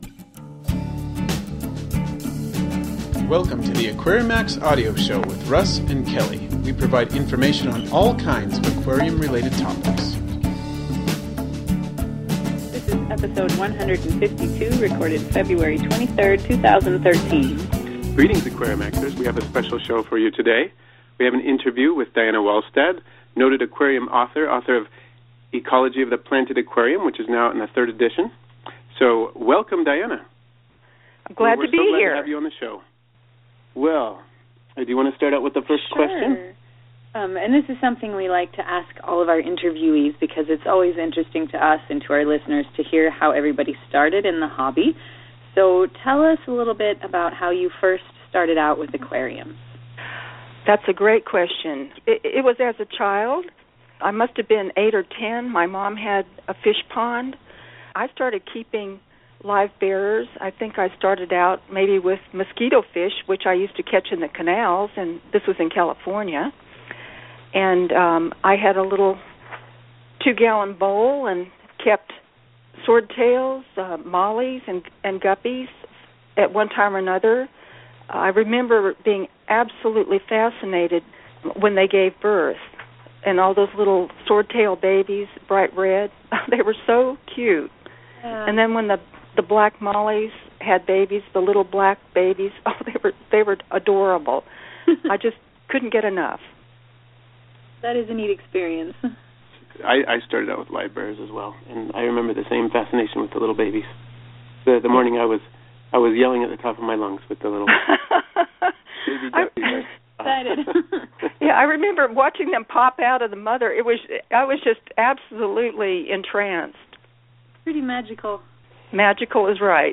interview | Aquarimax Pets